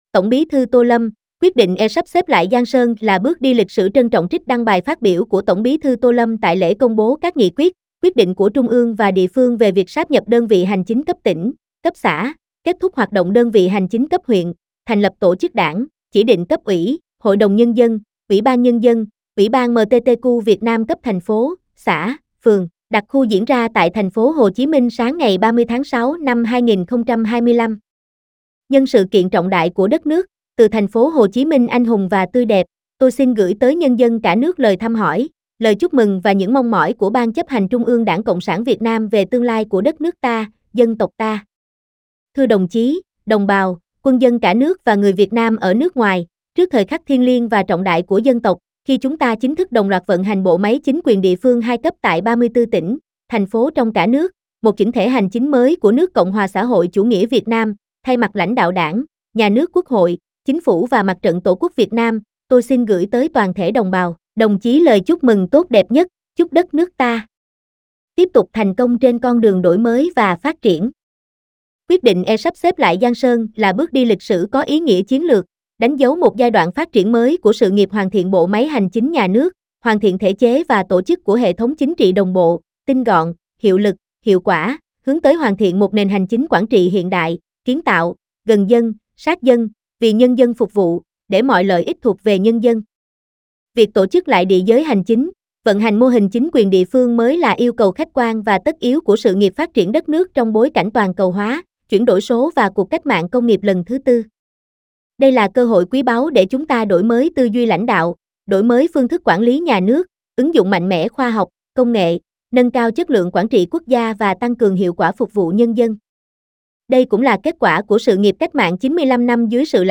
SÁCH NÓI: "VIỆT NAM KHÁT VỌNG VƯƠN MÌNH"